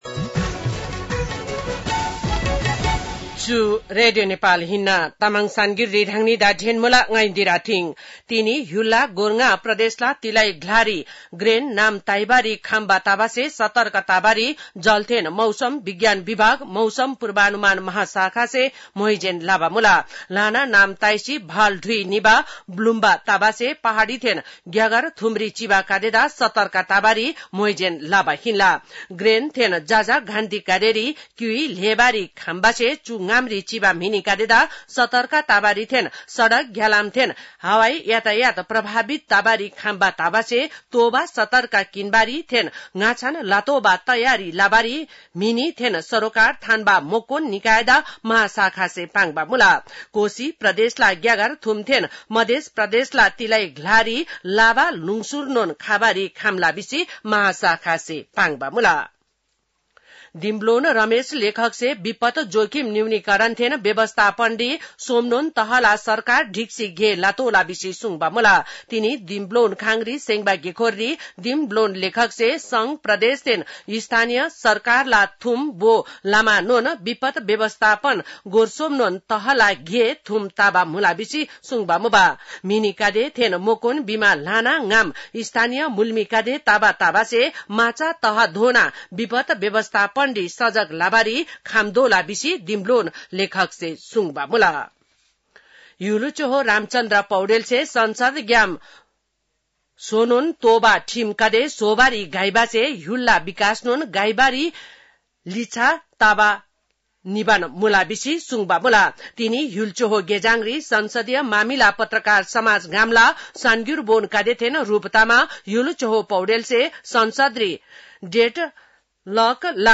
तामाङ भाषाको समाचार : ३२ असार , २०८२